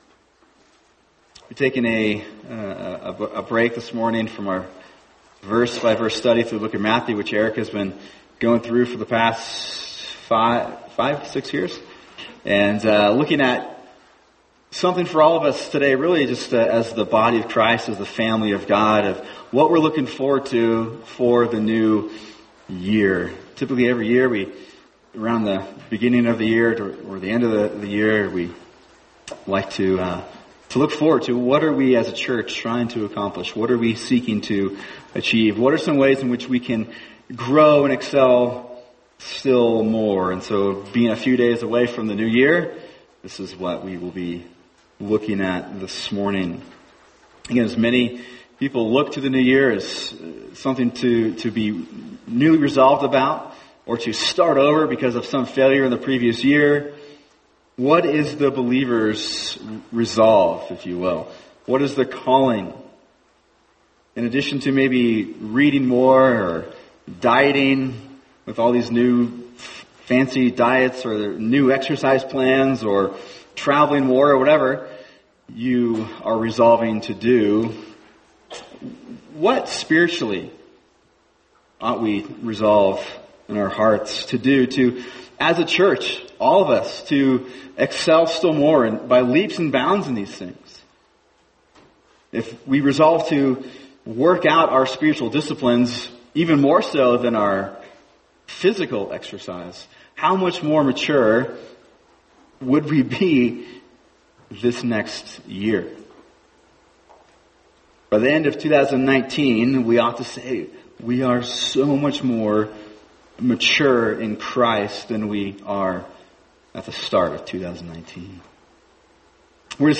[sermon] Ephesians 4:1-6 The Christian’s Call for the New Year | Cornerstone Church - Jackson Hole